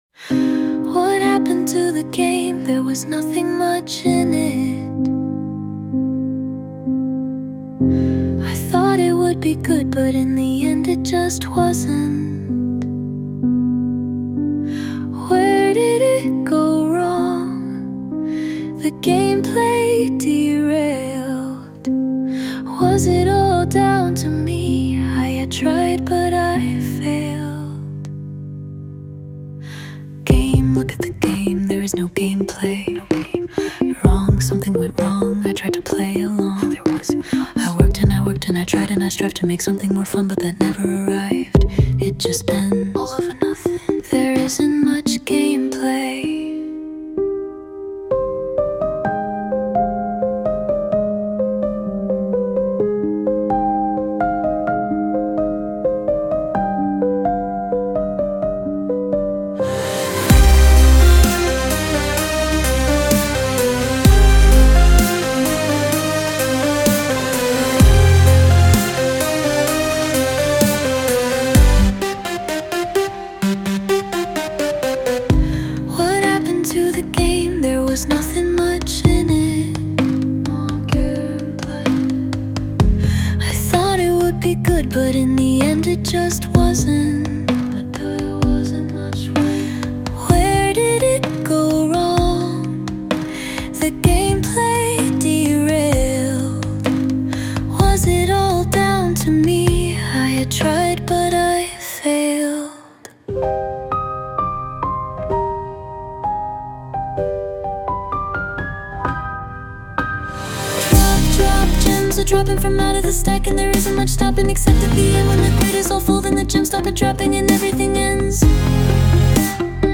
Sung by Suno
It_ends_when_it_ends_(Cover)_mp3.mp3